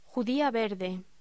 Locución: Judía verde
voz